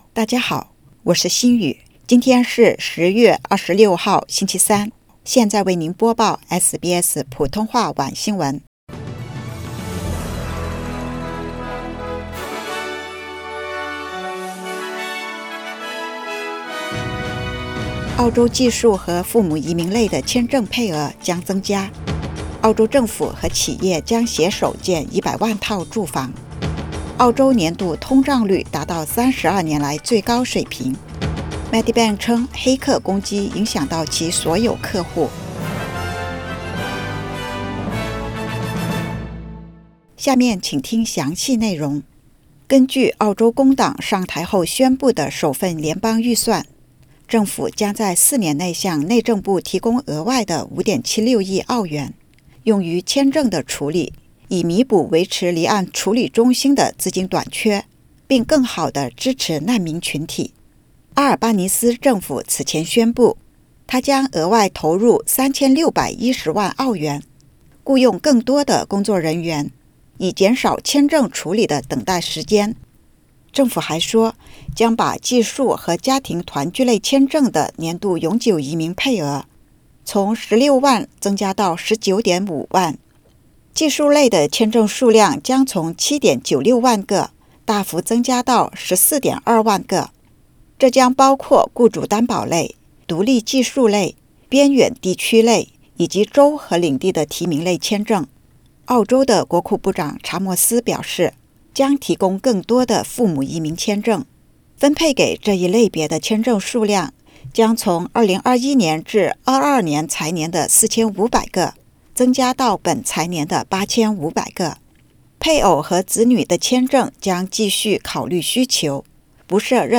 SBS晚新闻（2022年10月26日）